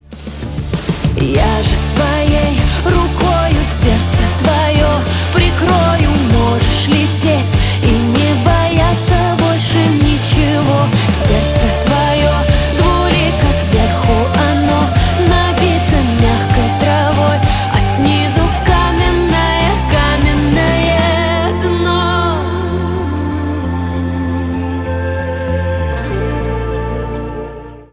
Загадочный и атмосферный рингтон с мистическим настроением.